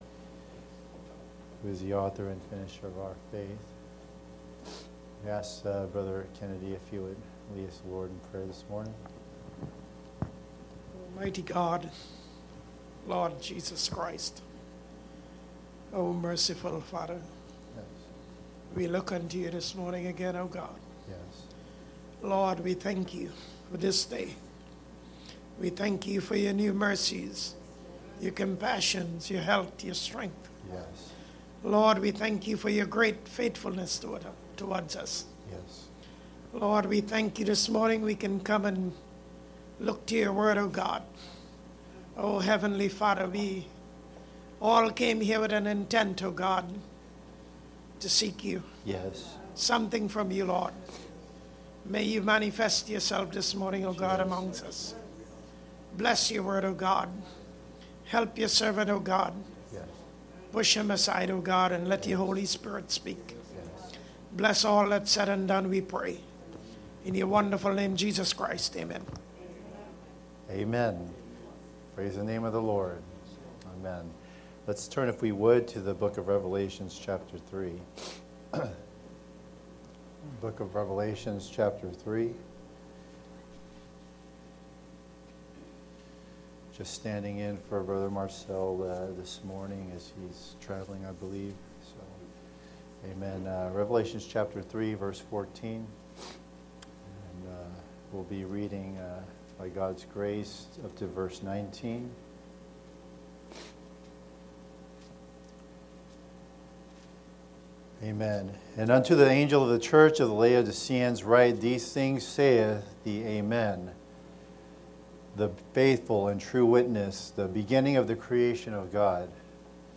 Fulfilling the Commission- Revival and Repentance (Sunday School)